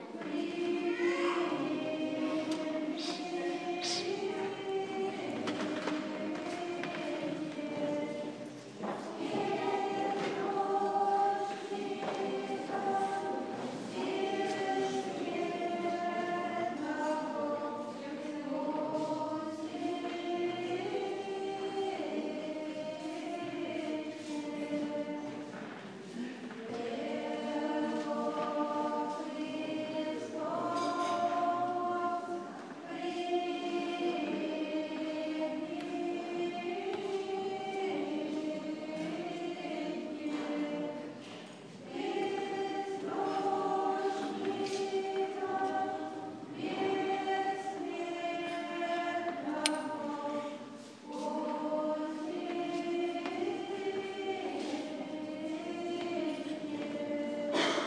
Причащение в церкви села Городище